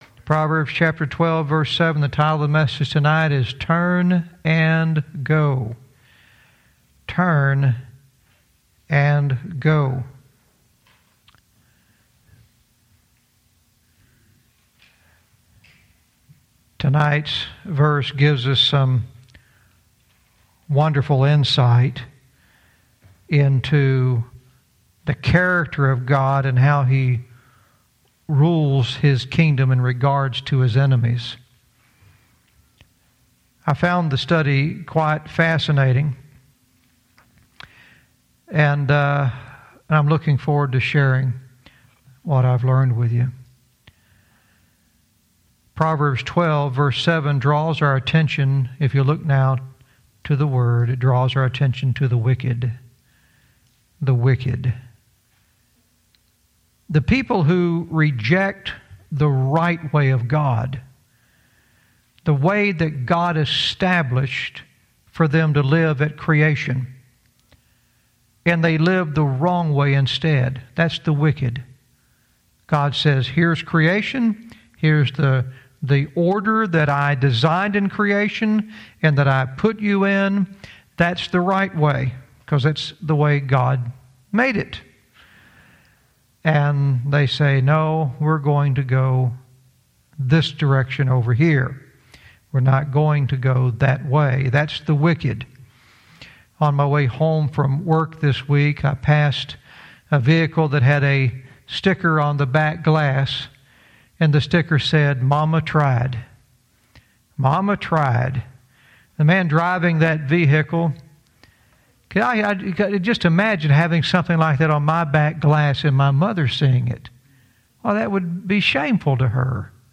Verse by verse teaching - Proverbs 12:7 “Turn and Go”